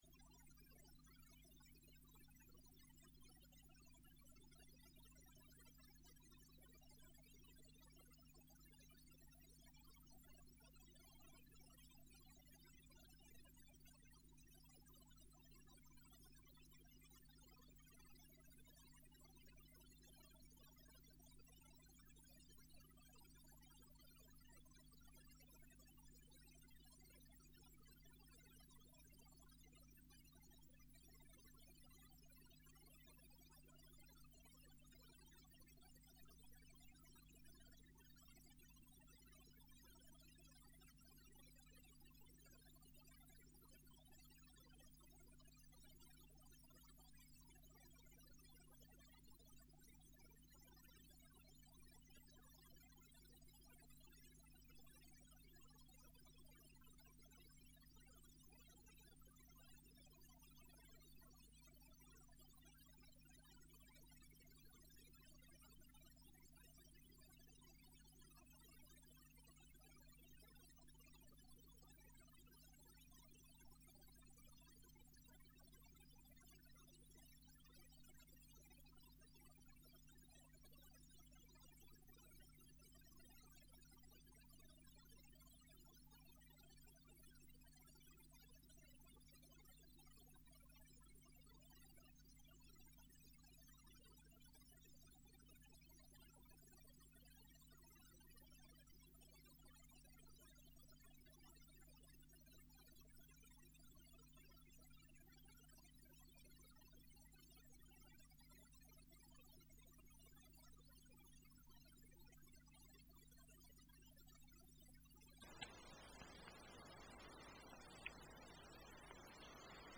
הנחיות מדיטציה - בוקר
דף הבית › ספריה › ספרית הקלטות הנחיות מדיטציה - בוקר הנחיות מדיטציה - בוקר 0:00 0:00 סוג ההקלטה: סוג ההקלטה: שיחת הנחיות למדיטציה שפת ההקלטה: שפת ההקלטה: עברית